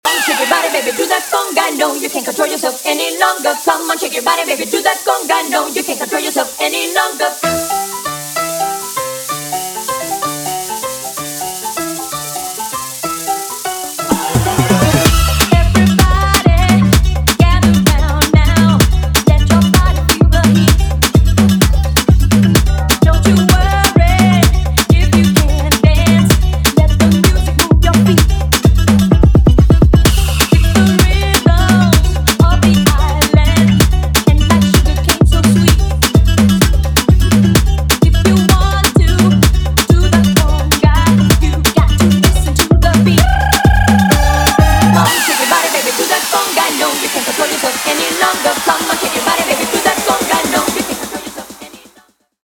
guaracha
EDM latino